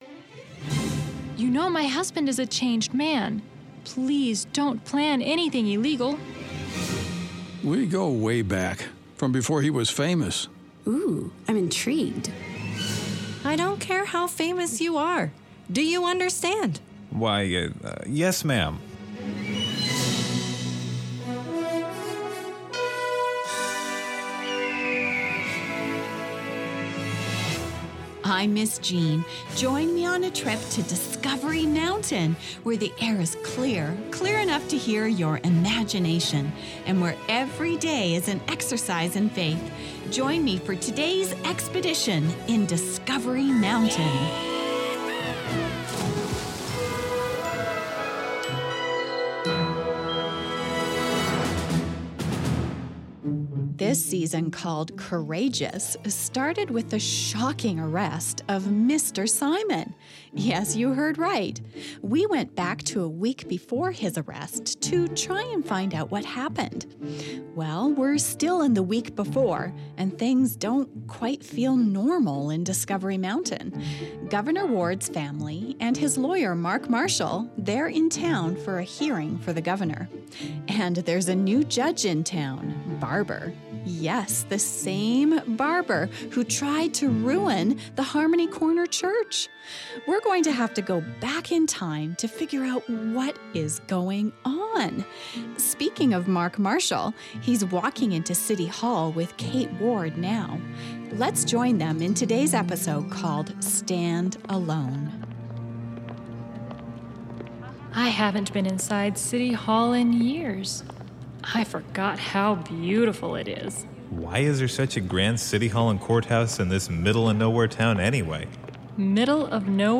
A dramatized audio program where listeners experience adventure, mystery, camp fire songs and, most importantly, get to know Jesus.